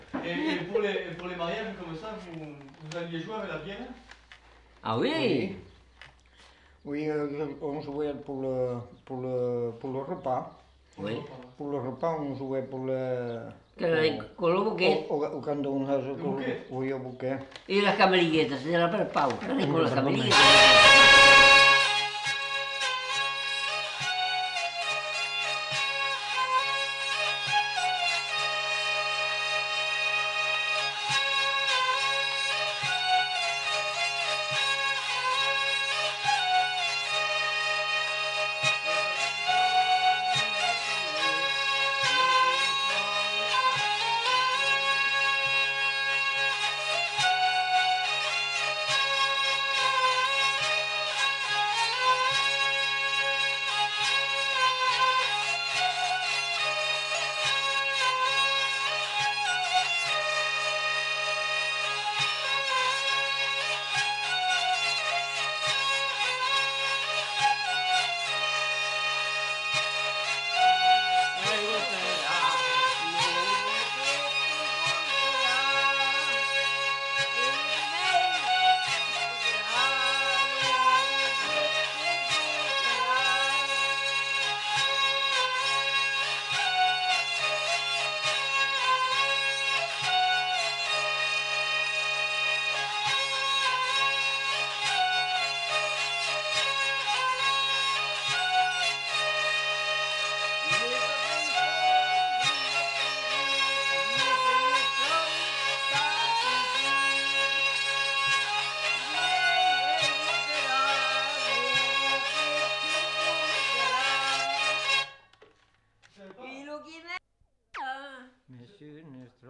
Aire culturelle : Gabardan
Lieu : Vielle-Soubiran
Genre : morceau instrumental
Instrument de musique : vielle à roue